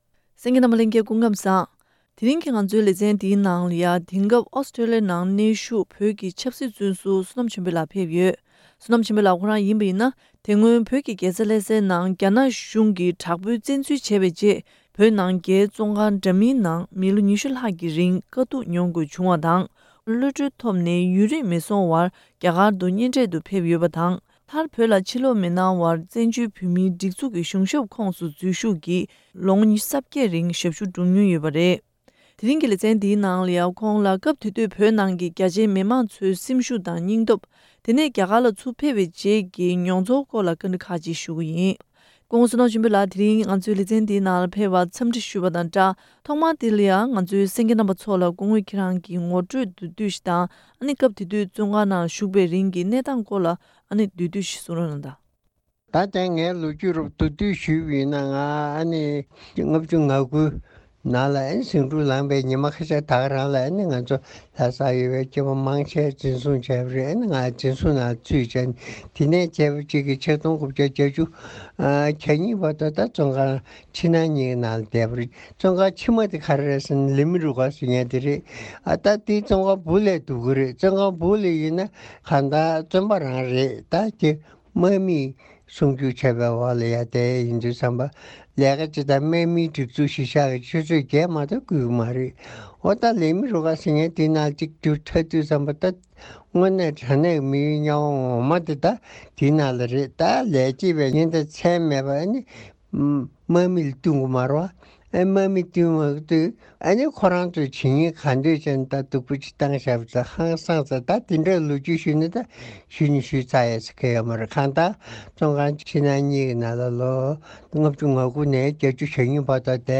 བཀའ་དྲི་ཞུས་པ་ཞིག་གསན་གནང་གི་རེད།